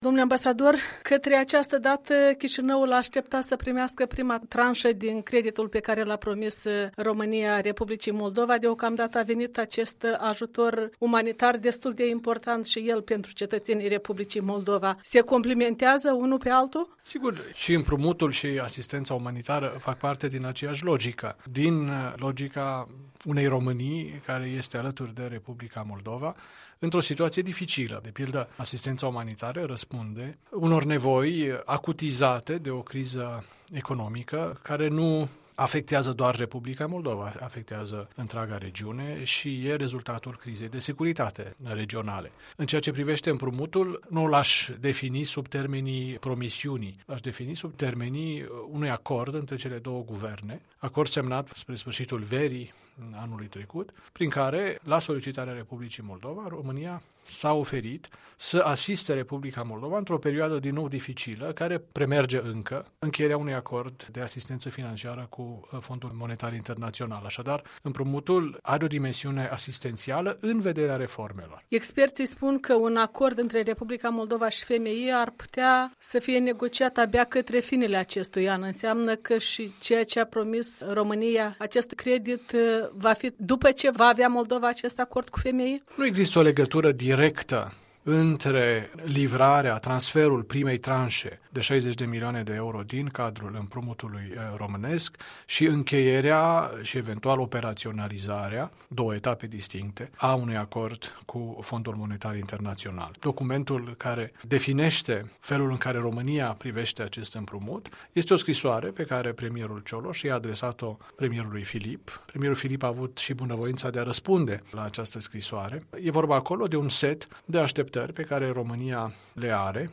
în dialog cu Marius Lazurca